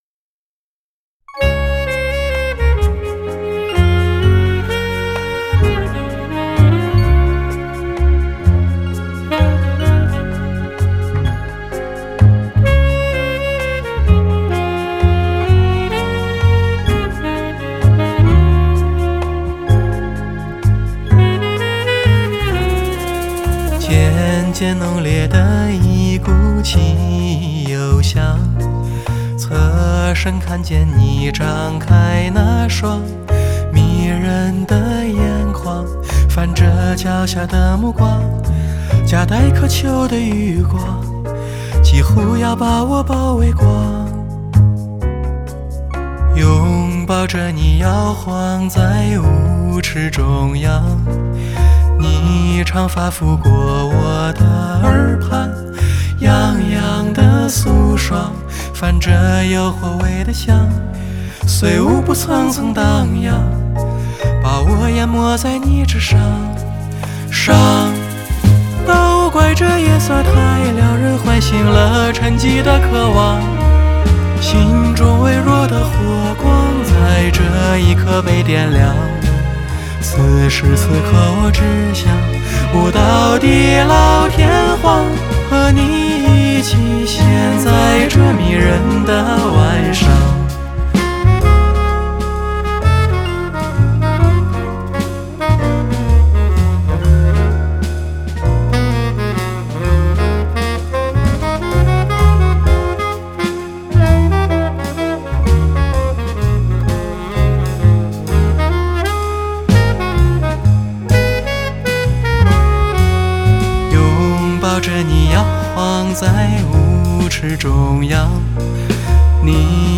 Here I'd like to share with you a laid-back and sultry retro jazz dance song I composed, titled "The Night Is Tantalizing". It tells the story of a couple dancing closely in the dance floor.